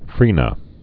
(frēnə)